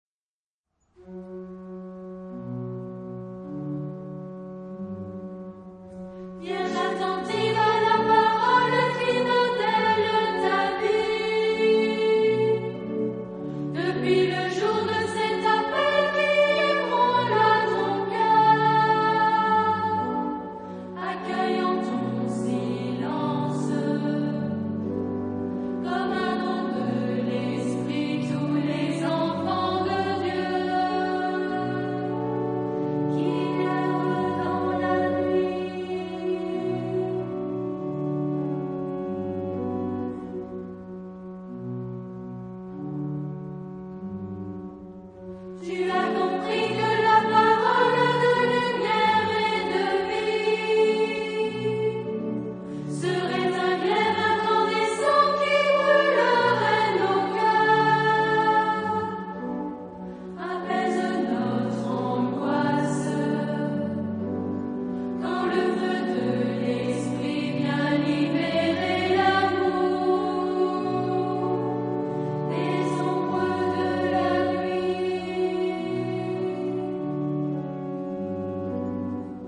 Genre-Style-Form: Hymn (sacred)
Mood of the piece: meditative ; calm
Type of Choir:  (1 unison voices )
Instrumentation: Organ  (1 instrumental part(s))
Tonality: G minor